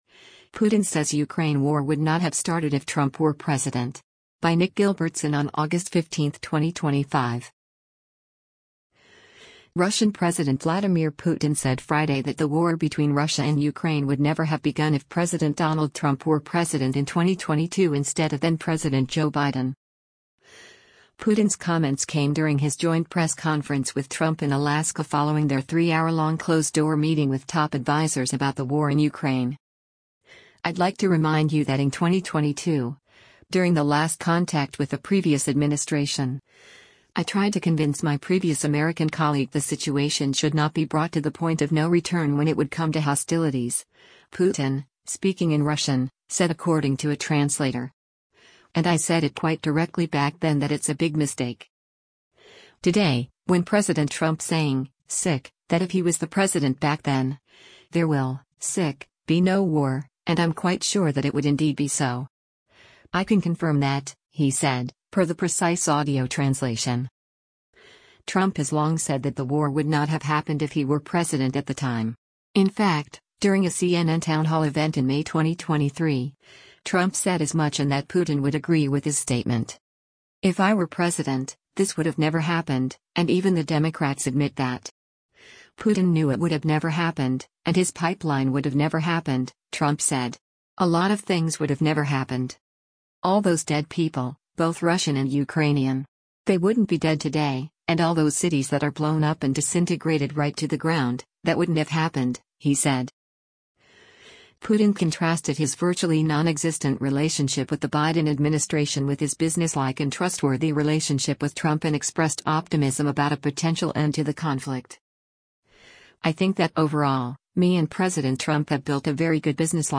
Putin’s comments came during his joint press conference with Trump in Alaska following their three-hour-long closed-door meeting with top advisers about the war in Ukraine.